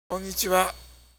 スピーキングバルブを使った肉声